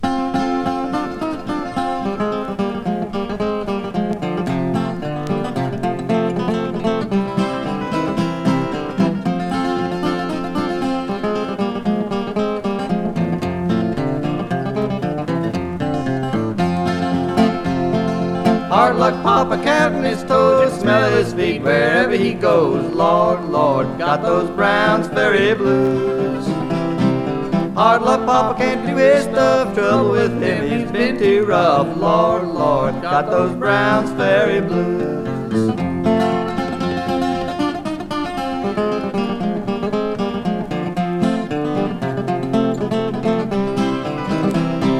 Folk, Bluegrass　USA　12inchレコード　33rpm　Mono